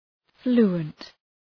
Προφορά
{‘flu:ənt}